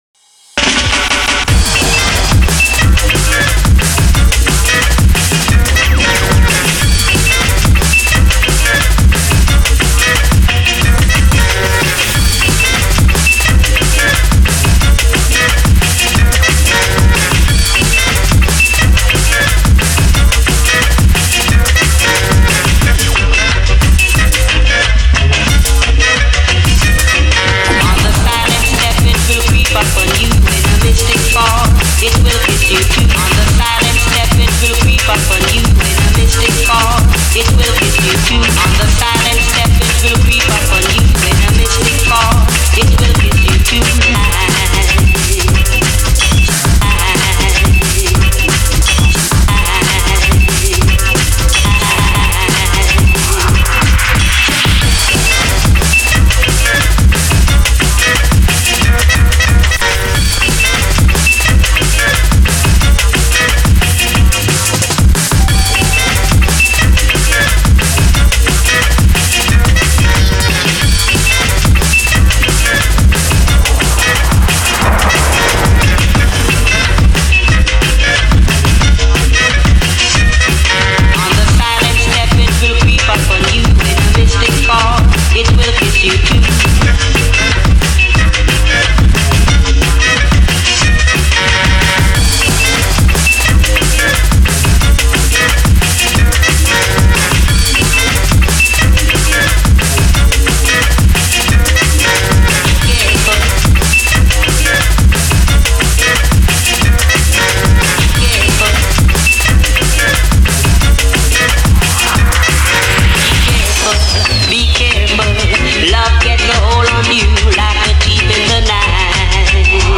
raggajungle Descàrregues i reproduccions